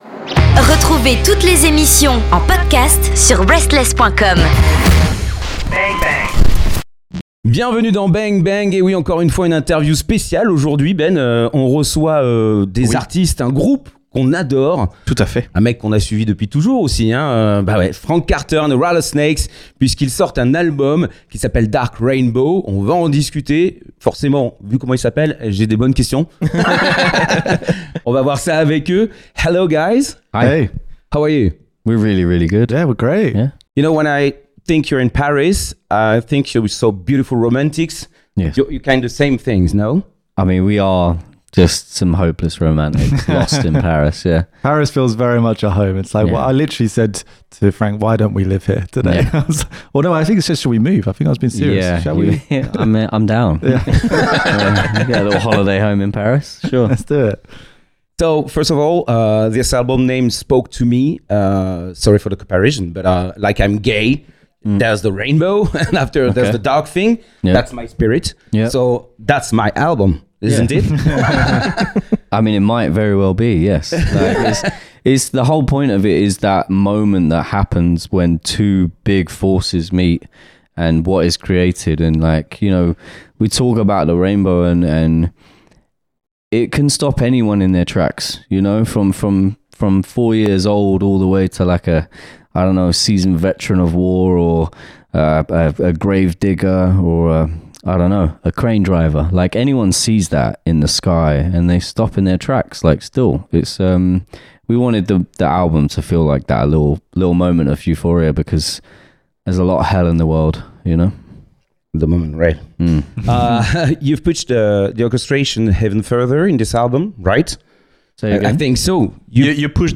Interview FRANK CARTER & THE RATTLESNAKES